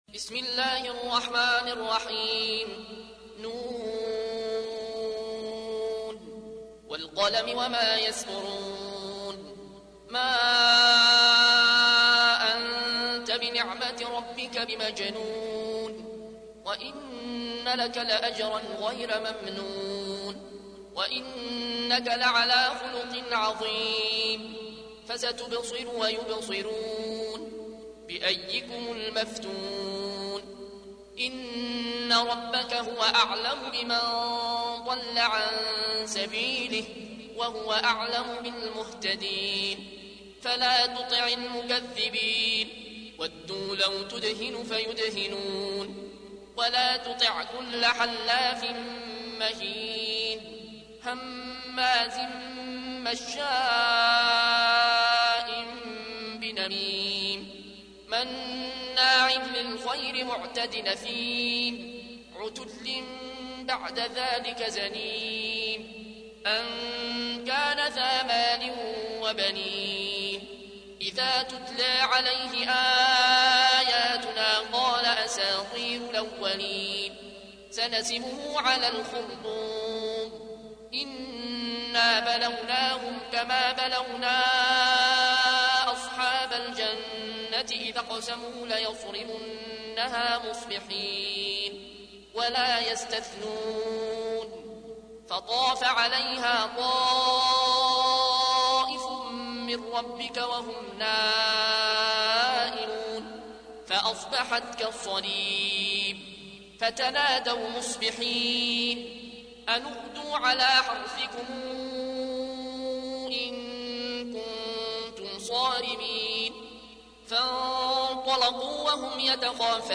تحميل : 68. سورة القلم / القارئ العيون الكوشي / القرآن الكريم / موقع يا حسين